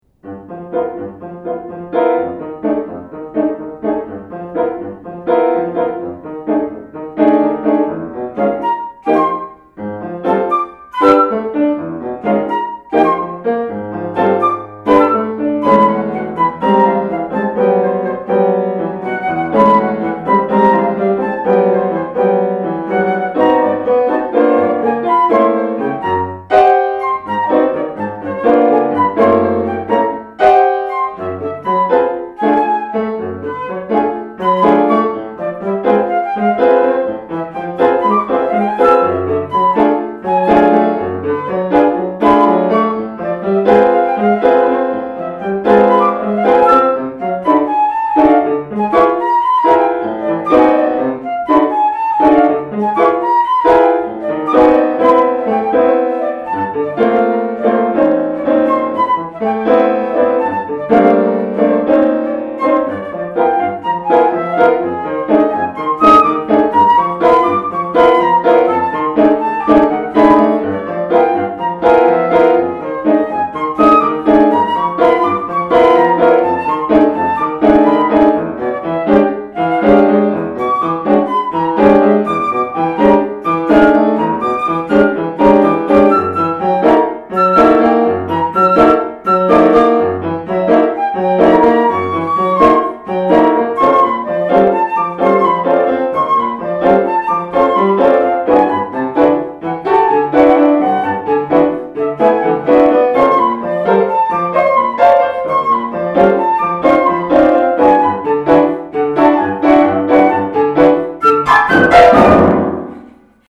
excerpt_from_piloes_nov2019_rehearsal.mp3